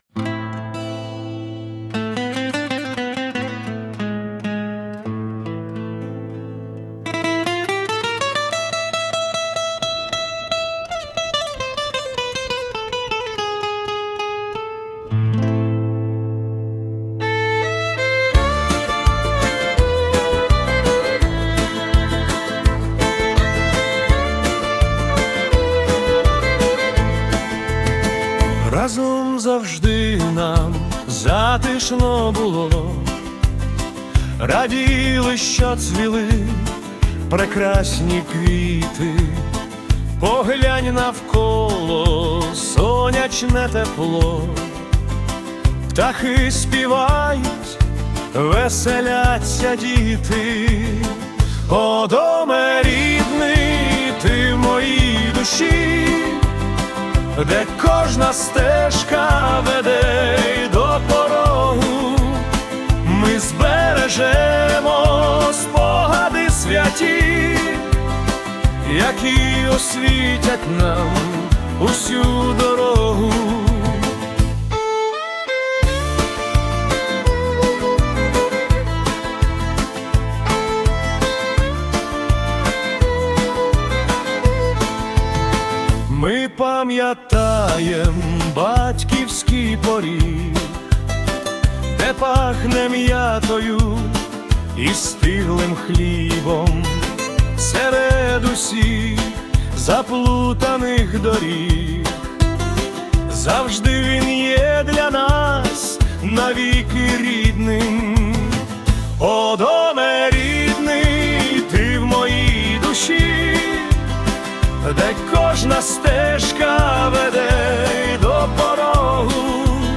🎵 Жанр: Фолк-поп / Родинна пісня
Акордеон та гітара створюють атмосферу родинного затишку.